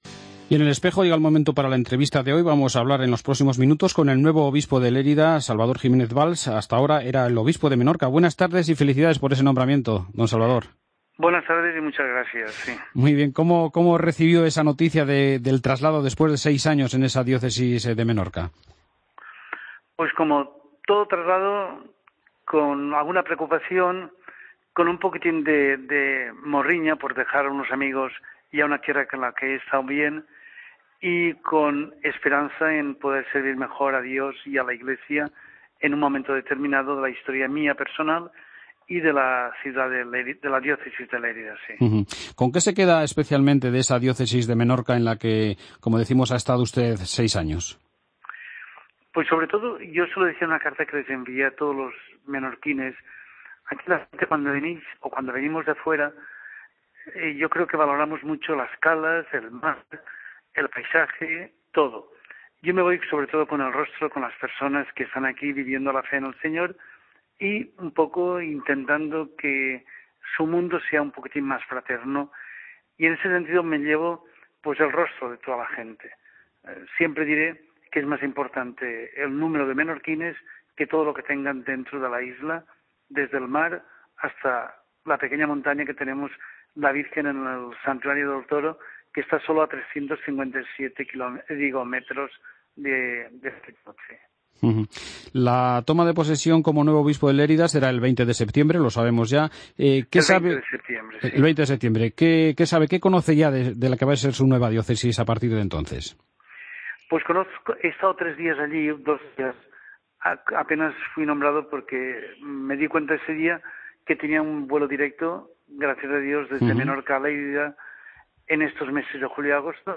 AUDIO: Escucha la entrevista al nuevo Obispo de Lérida, monseñor Salvador Giménez Valls , en El Espejo
Madrid - Publicado el 10 ago 2015, 15:27 - Actualizado 13 mar 2023, 17:48